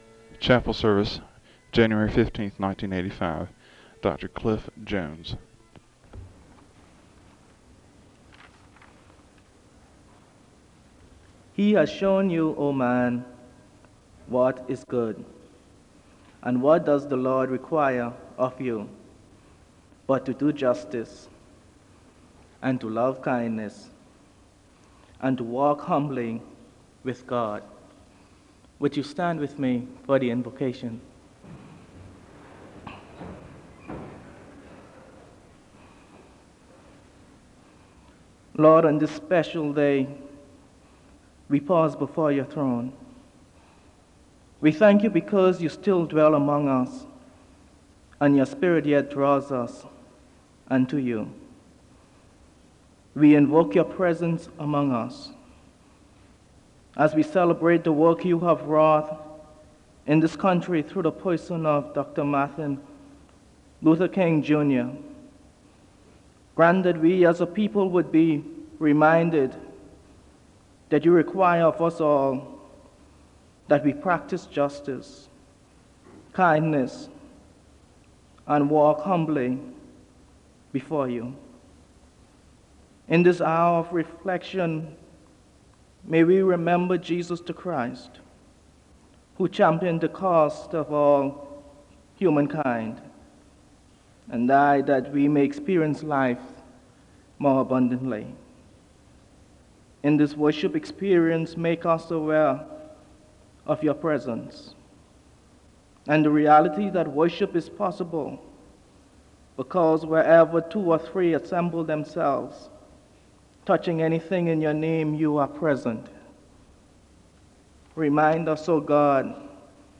The service begins with a Scripture reading from Micah 6:8 and a moment of prayer (0:00-2:17). There is a Scripture reading from Luke 4:16-23 (2:18-3:49).
The service closes with a benediction (18:13-18:28).